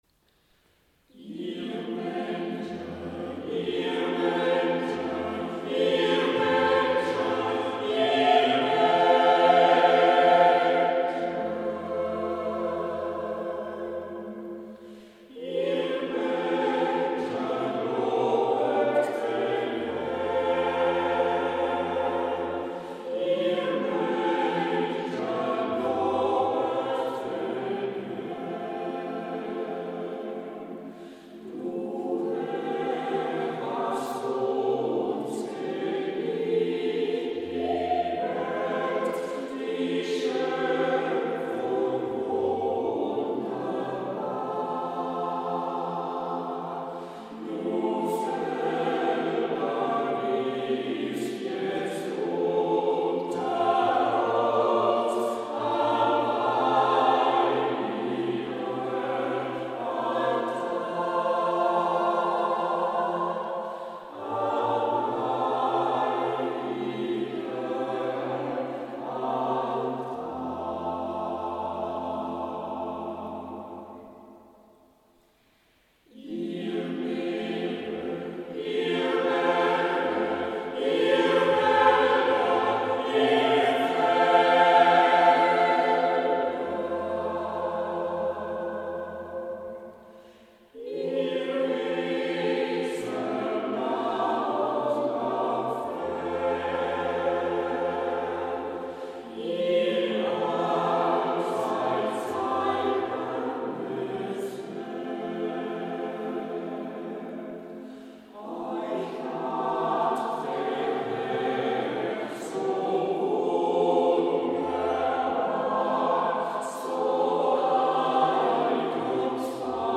Chor
Der_Schoepfung_Lob_Chor.mp3